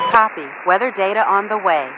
Added voiceovers for "vector to airbase" and "weather report" calls
Radio-atcWeatherUpdate4.ogg